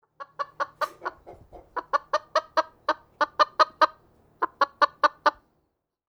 chicken-heck-sound